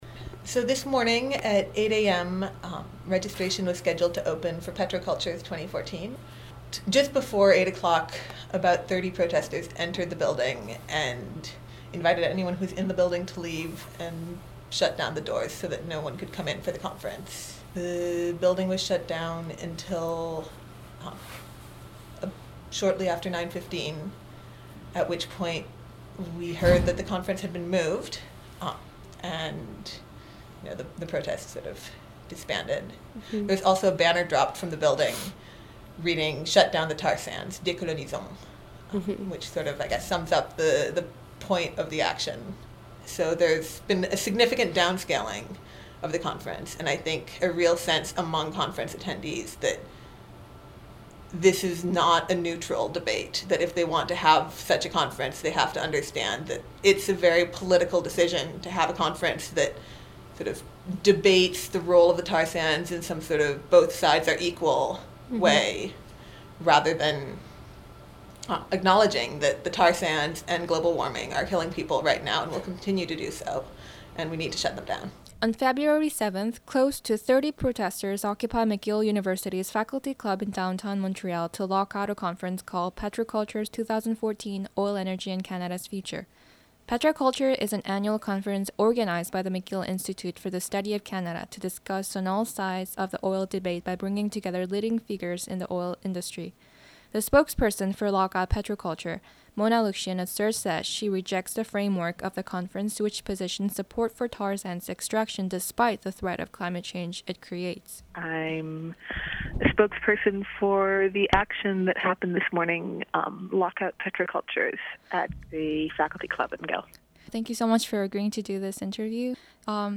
petrocultures_report_mixdown_1.mp3